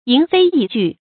發音讀音